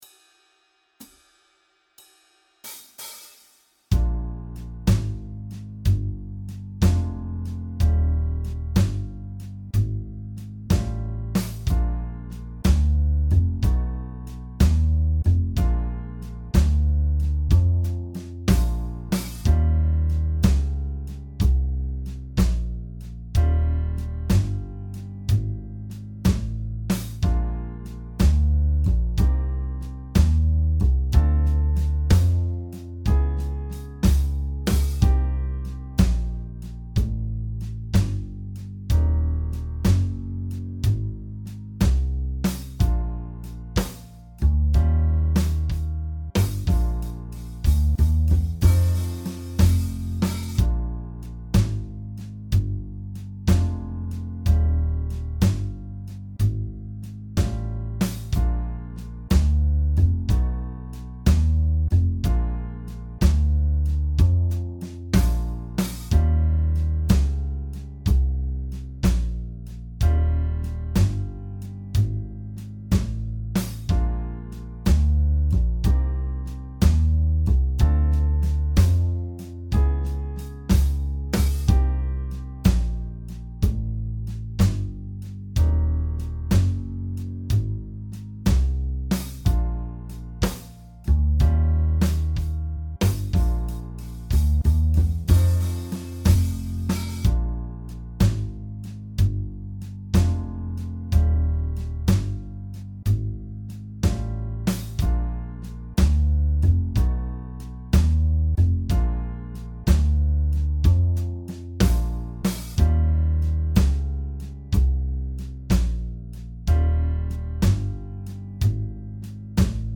Jam Track
Original jam track